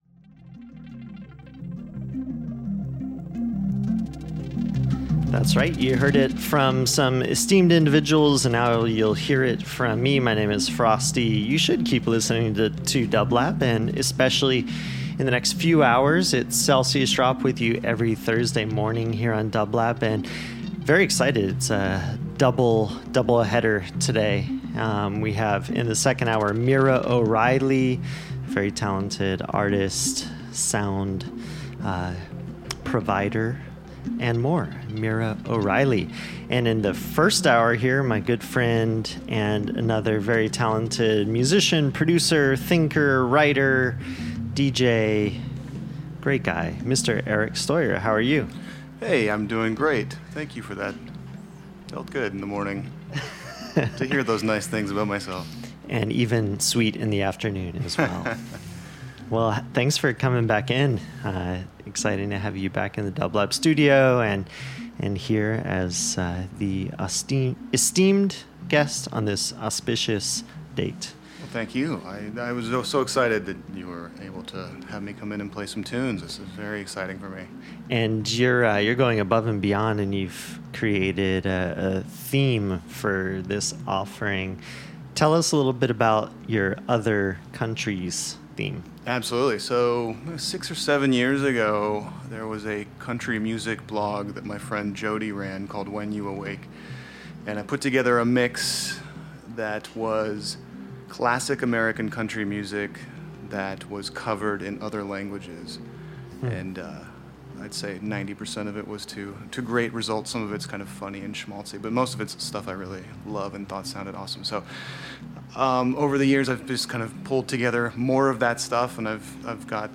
American country songs covered in other languages